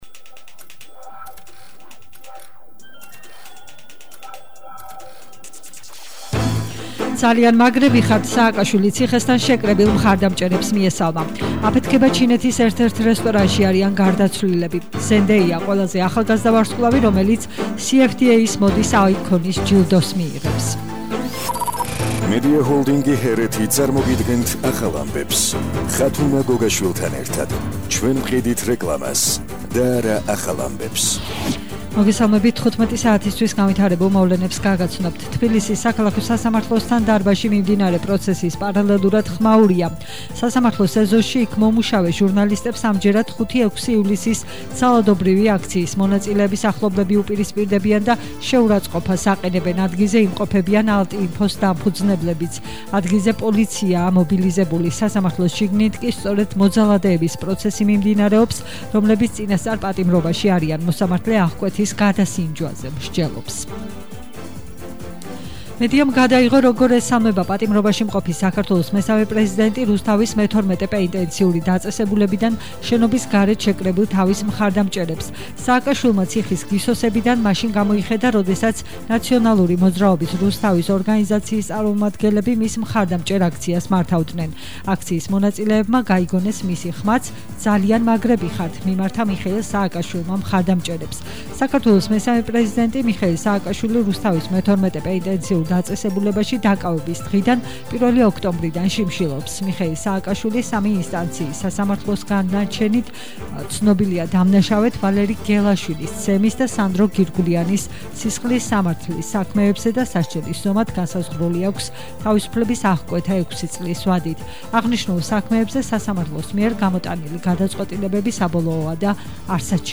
ახალი ამბები 15:00 საათზე –21/10/21 - HeretiFM